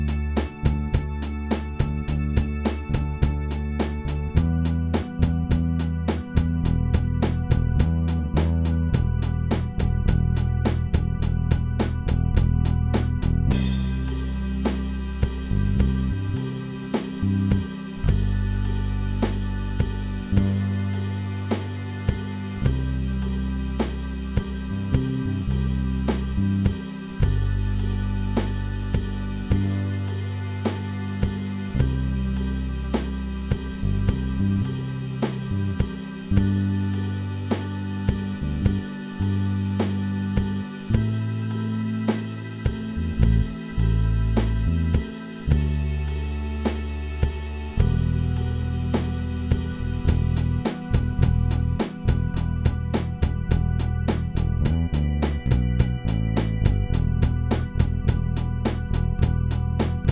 This is a very early partial take of a song going onto the album. This specific part is the chorus of the song and it shows how the song transitions in sound between the chorus and verses.
I’ve been putting a lot of time into this one and what you hear is an organ, bass, and drums. The organ and drums are midi, but I think it’s coming out pretty nice!
rock folk music